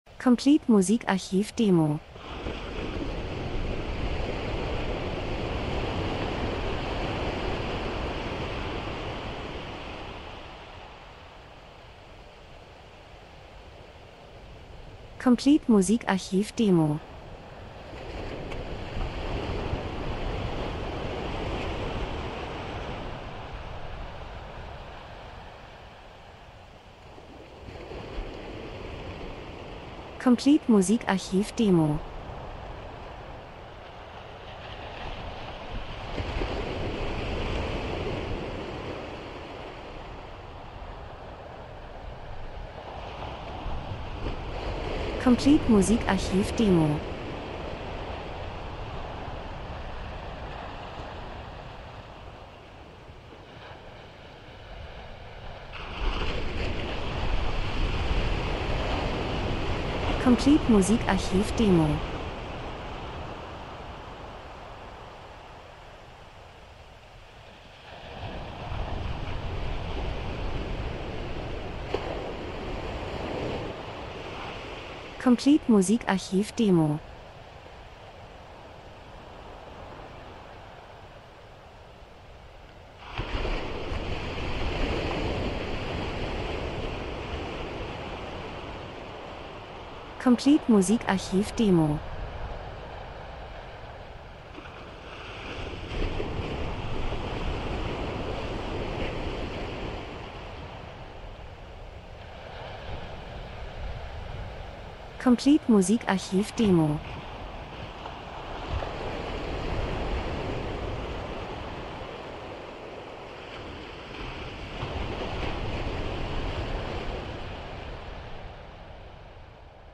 Sommer -Geräusche Soundeffekt Natur Meer Wellen Strand 02:00